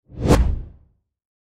Quick Swoosh Transition Sound Effect
Description: Quick swoosh transition sound effect. A fast and smooth whoosh sound perfect for video transitions, animations, trailers, games, or edits that need speed and motion.
Quick-swoosh-transition-sound-effect.mp3